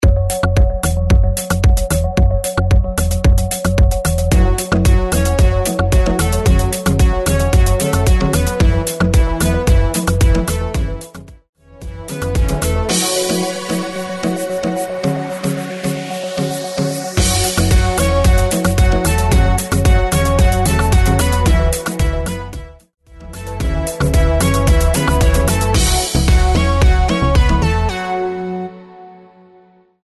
112 BPM
Smooth Gqom
Gqom